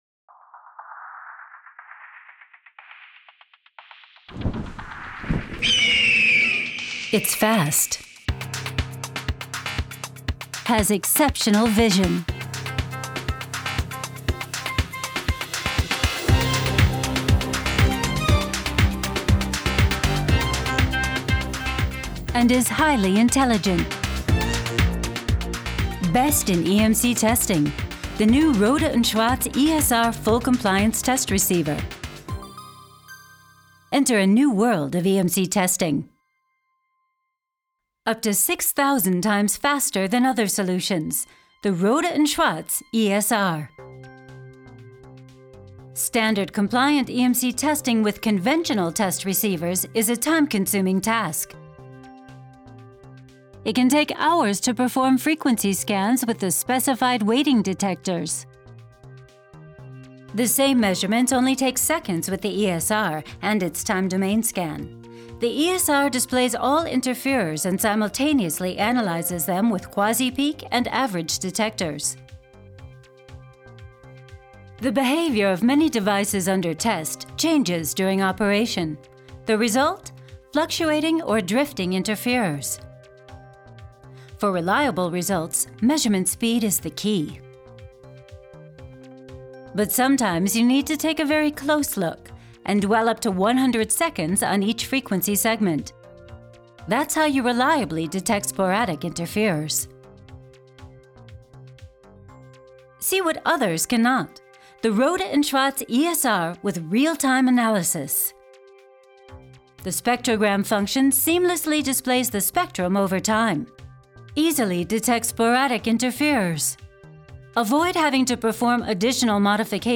dunkel, sonor, souverän, plakativ
Commercial (Werbung)